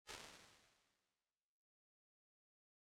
228-R2_LargeRoom.wav